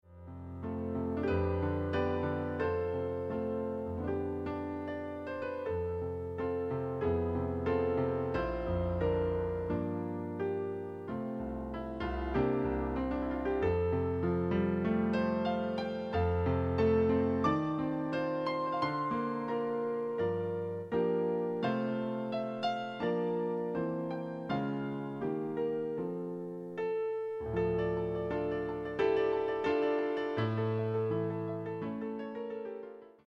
Early advanced sacred piano arrangement, 3 pgs.